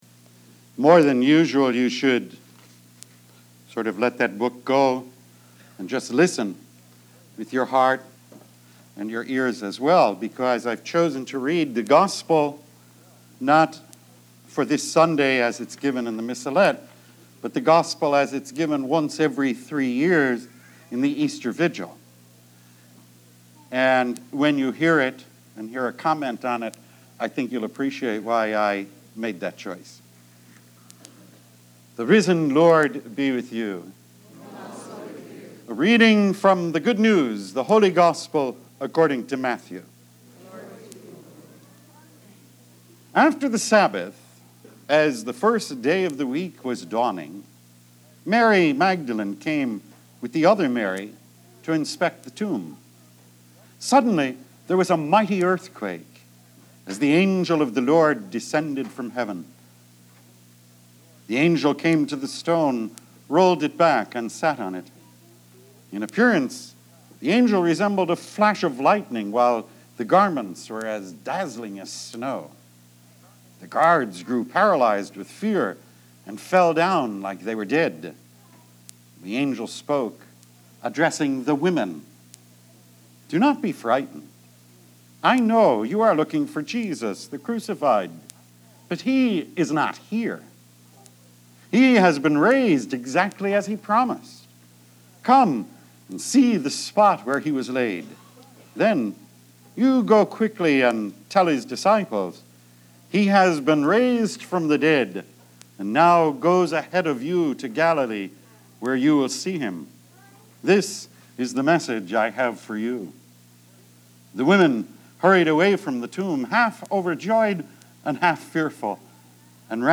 Women – Weekly Homilies